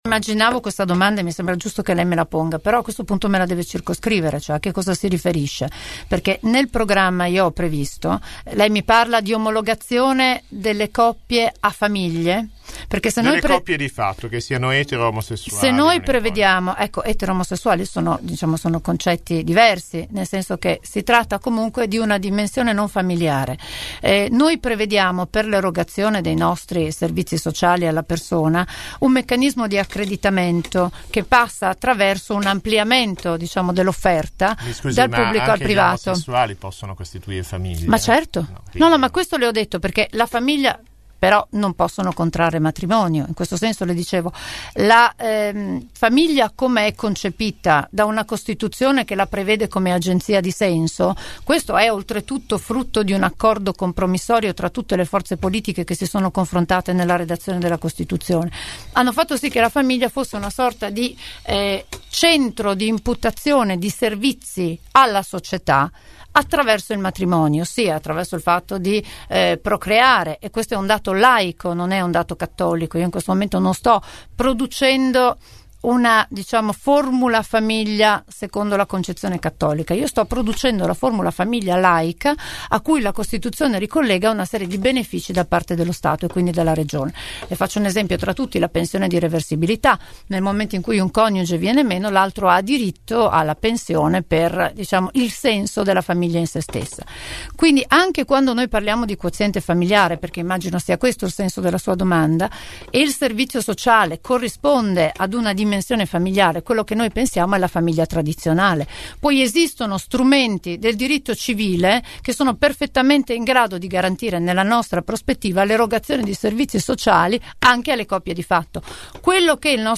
Ecco una sintesi dell’intervista andata in onda all’interno di Angolo B.